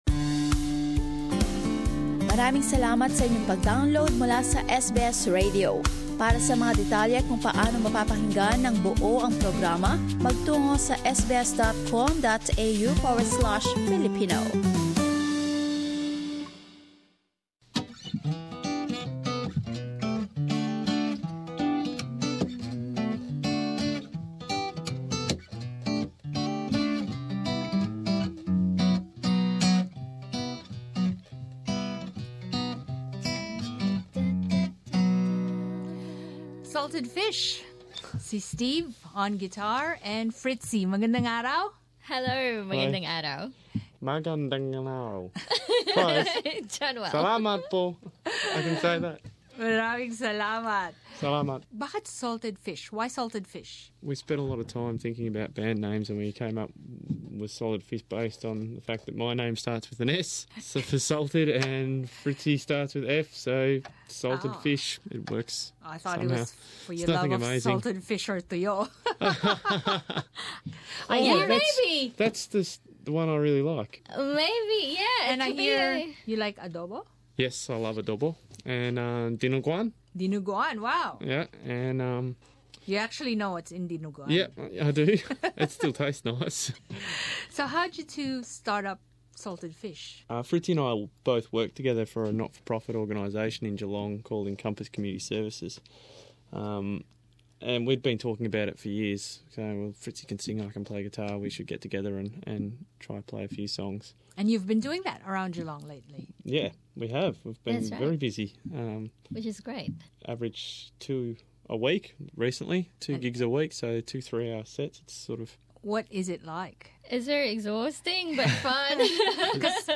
Salted Fish, Pilipino Australyanong acoustic duo
Salted Fish recording at SBS Radio, Federation Square, Melbourne Source: SBS Filipino